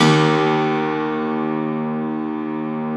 53a-pno03-D0.aif